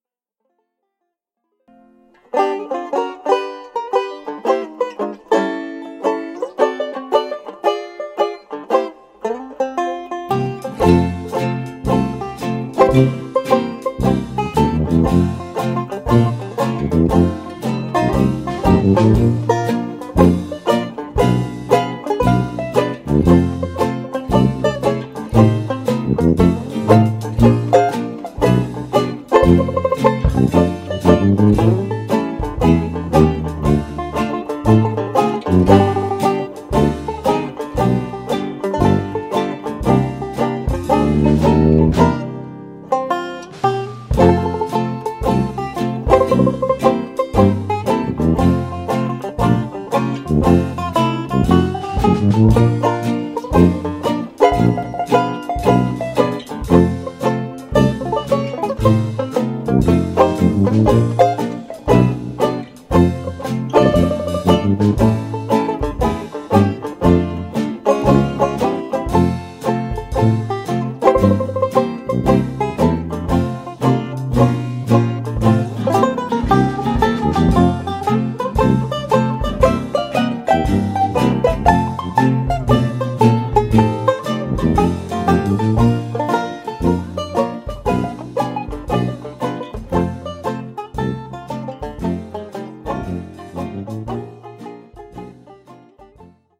8-beat intro.
plectrum banjo